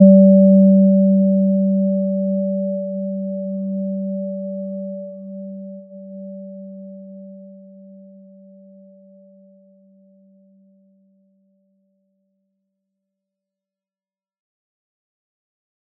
Gentle-Metallic-1-G3-p.wav